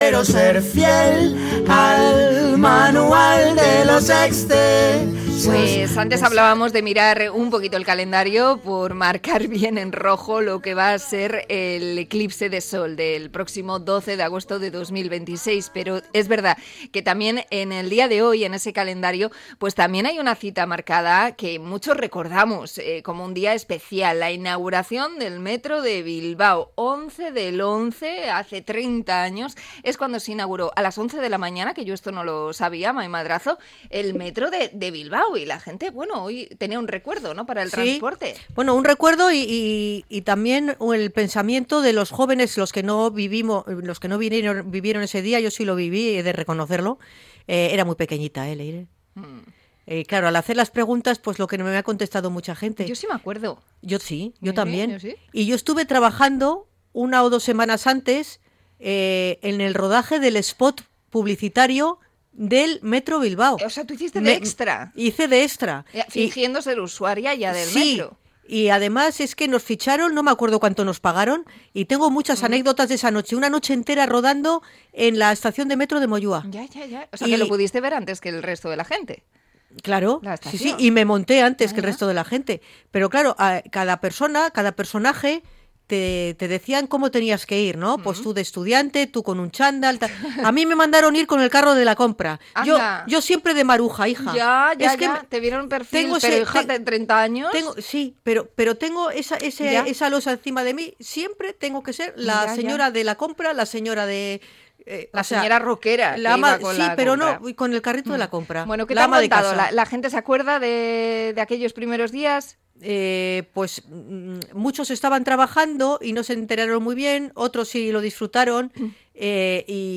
Encuesta callejera sobre el aniversario de Metro Bilbao